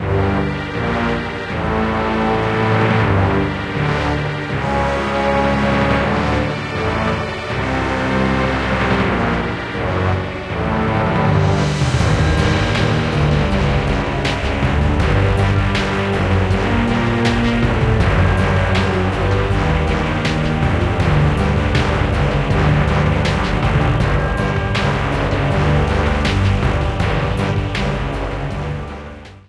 Ripped from the game
applied fade-out